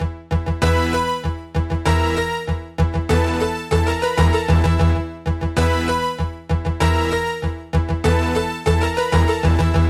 描述：Third loop is done with reversed P6 lead synths and heavy piano chords lightly reverbed Fl 8xxl + Adobe audition 3; use as you feel
标签： 97 bpm Hip Hop Loops Synth Loops 1.67 MB wav Key : Unknown